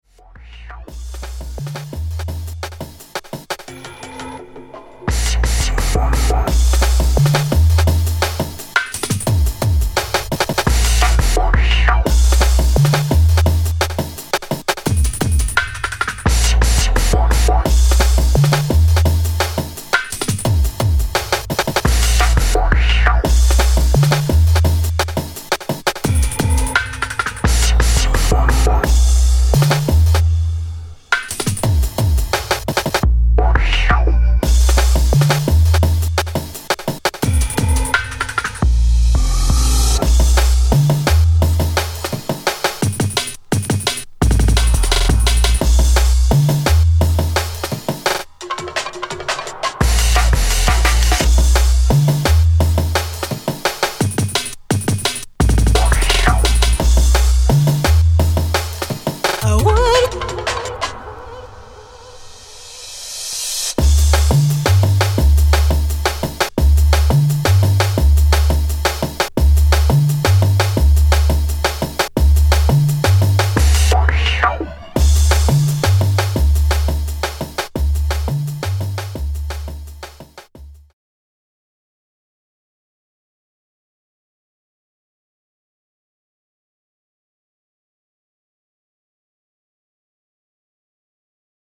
Jungle/Drum n Bass
Drum & Bass